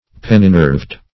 Search Result for " penninerved" : The Collaborative International Dictionary of English v.0.48: Penninerved \Pen"ni*nerved`\, a. [L. penna feather + E. nerve.] Pinnately veined or nerved.
penninerved.mp3